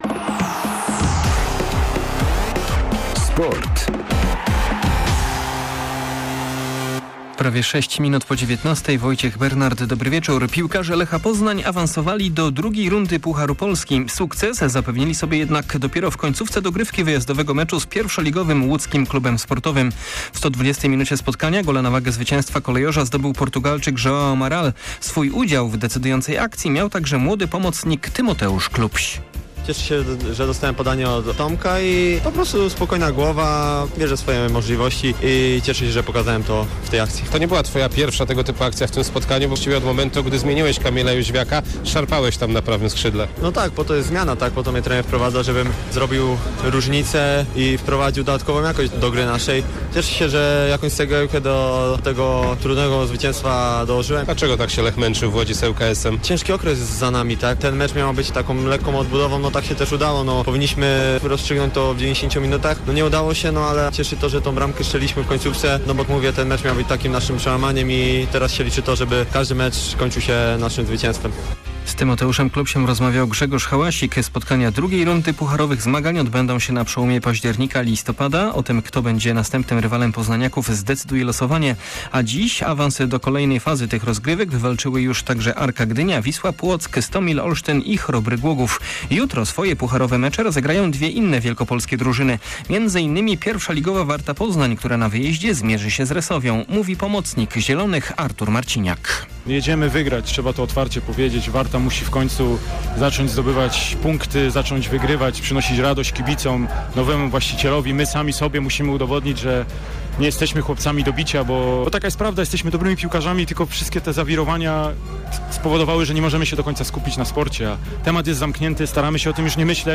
25.09. SERWIS SPORTOWY GODZ. 19:05